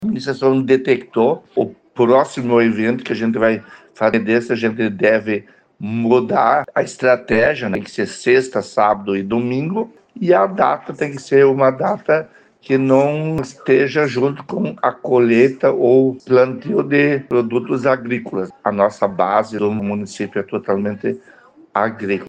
No entanto, em razão da colheita da safra de inverno e plantio da soja, o agronegócio não teve grande presença, por isso, uma das alterações para novas edições da Rua de Negócios é do período, ou seja, não poderá ocorrer em épocas de safras. )Abaixo, áudio de Paulo).